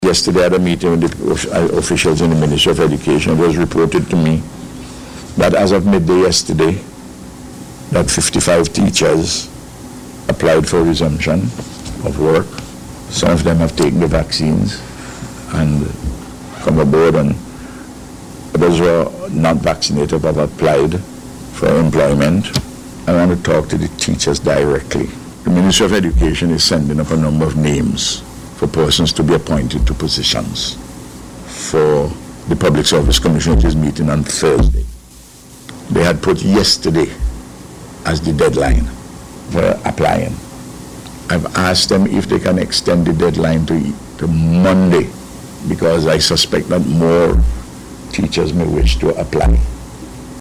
This was among several issues addressed by the Prime Minister at a Media Conference yesterday, at Cabinet Room.